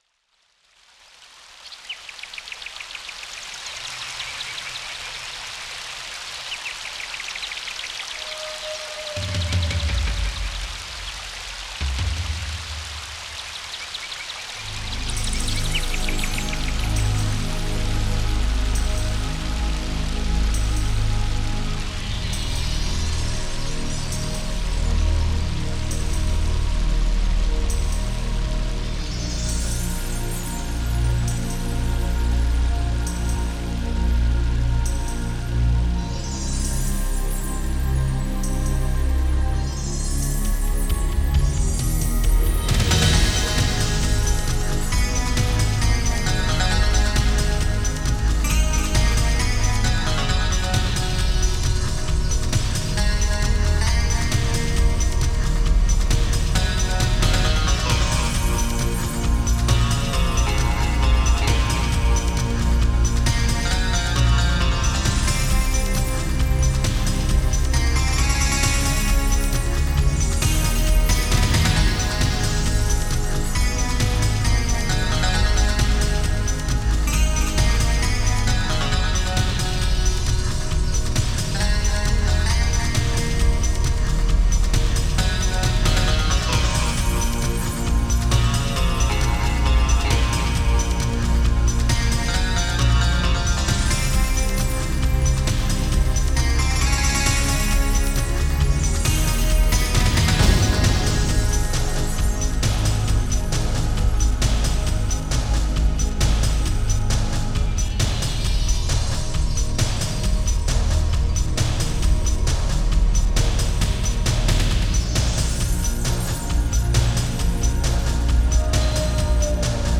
Style: Ambient
very good sample quality